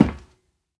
StepMetal2.ogg